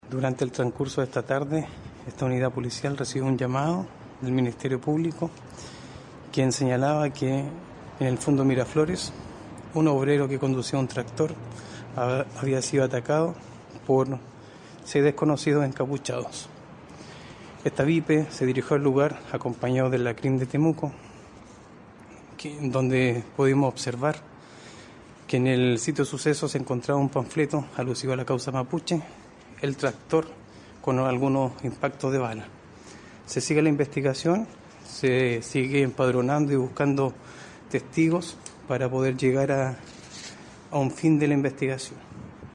Cuña-comisario-.mp3